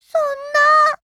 贡献 ） 协议：Copyright，其他分类： 分类:语音 、 分类:少女前线:UMP9 您不可以覆盖此文件。
UMP9_0_LOWMOOD_JP.wav